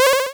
Train-of-Thought - Retro 8-Bit Game Jam — May 10th - 18th 2020 (1 week)
Checkpoint.wav